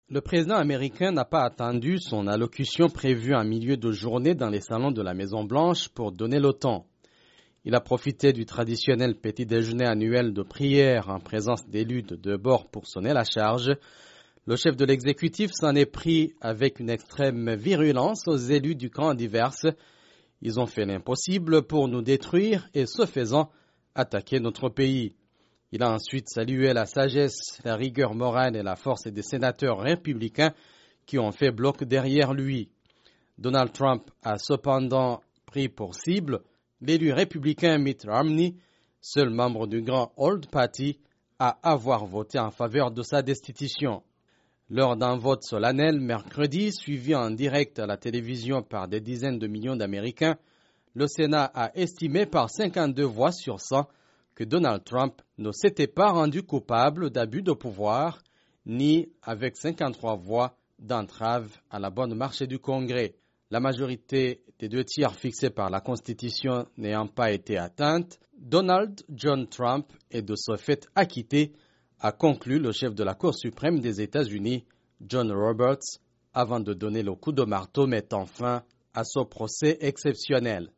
Trump attaque les démocrates lors d'une rencontre de groupes religieux